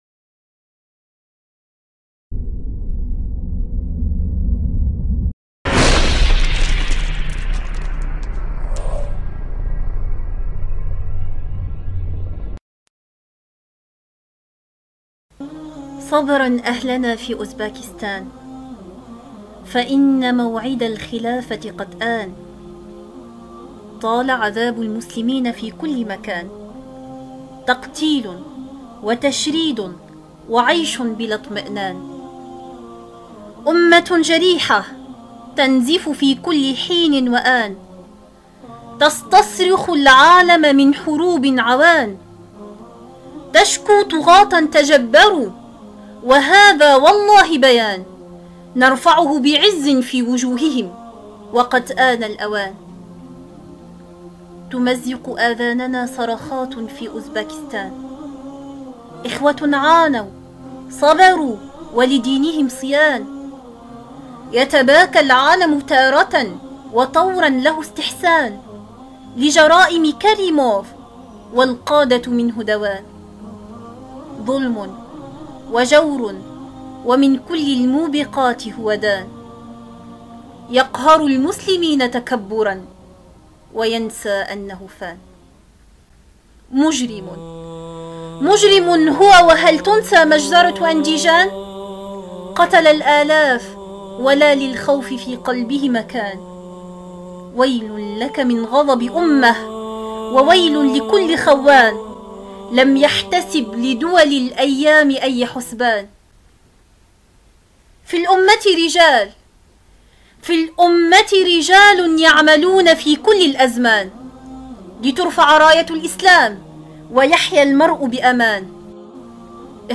قصيدة - صبراً أهلنا في أوزبيكستان فإنّ موعد الخلافة قد آن!